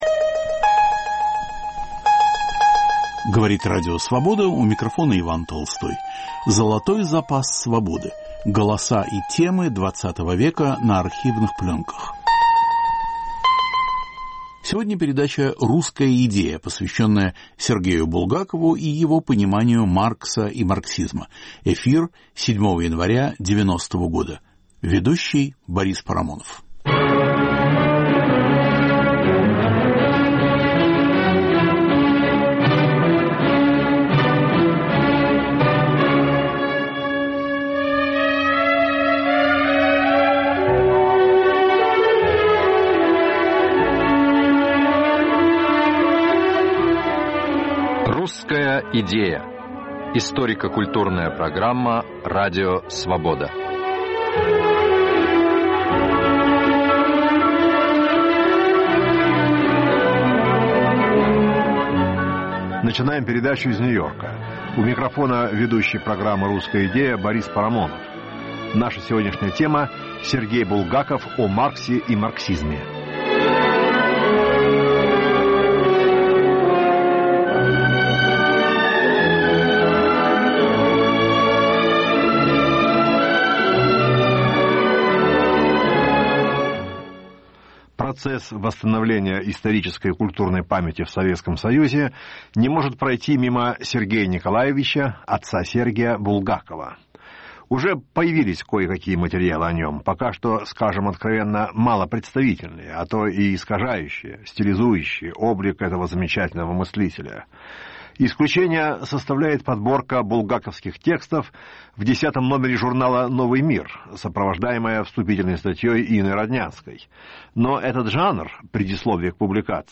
Золотой запас Свободы. Голоса и темы XX века на архивных пленках.